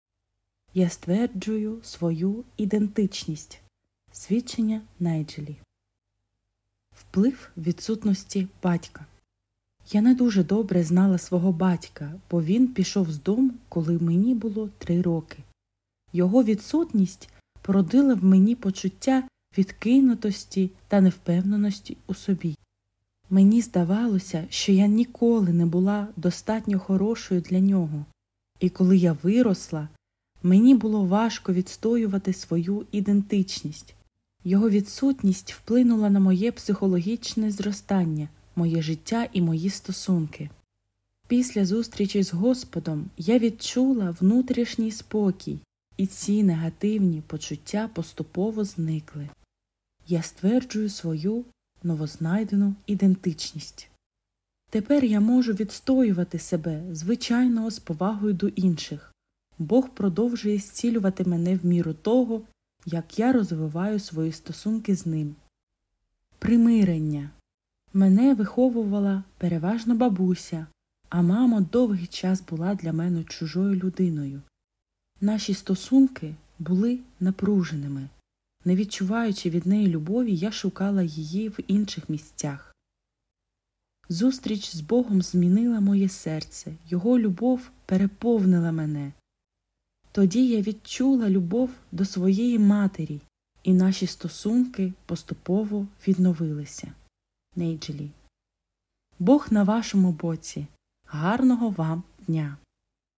Аудіо, Свідчення